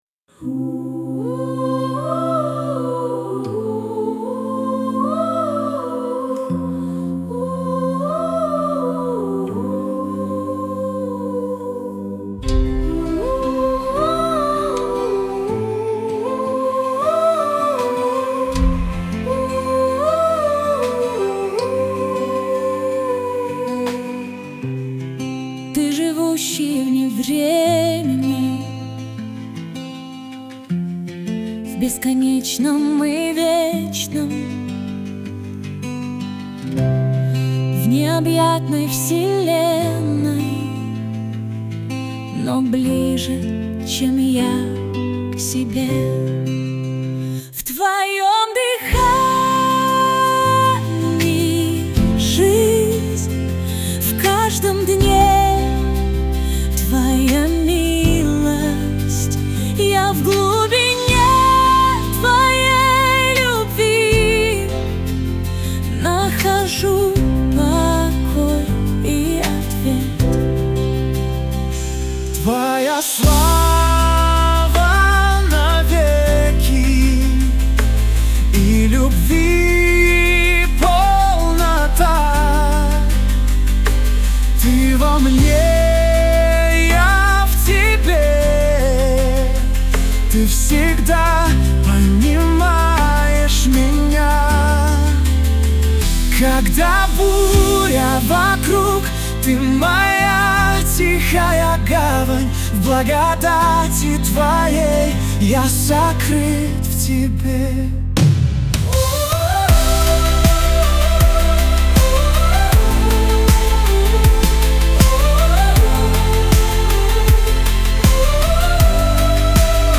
песня ai
82 просмотра 104 прослушивания 11 скачиваний BPM: 79